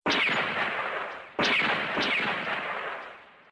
toypistol
描述：Toy electric gun.
标签： gun toy weapon gunshot shoot
声道立体声